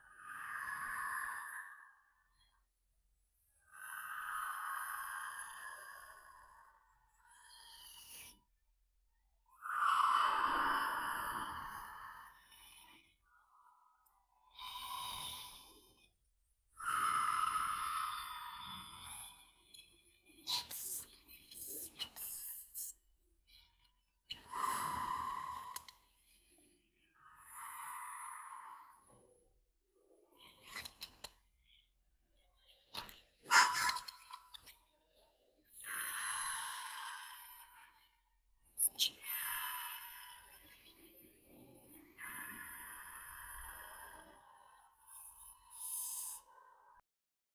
small-scary-goblin.flac